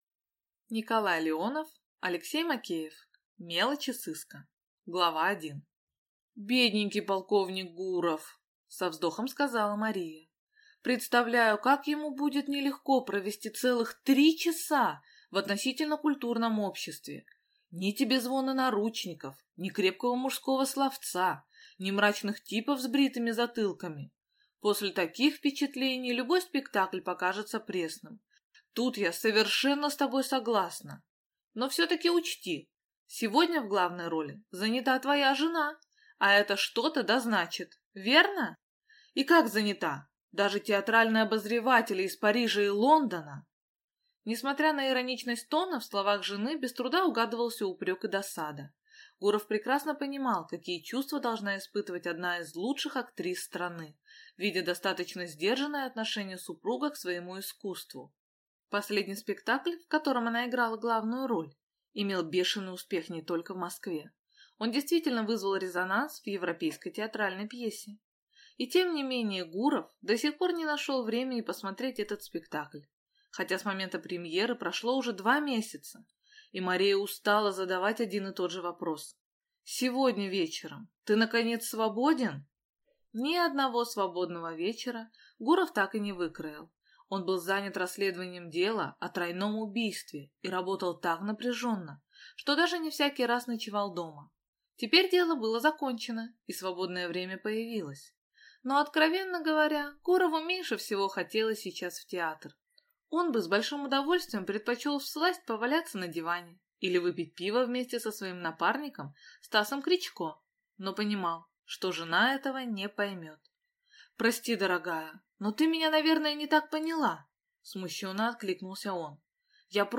Аудиокнига Мелочи сыска | Библиотека аудиокниг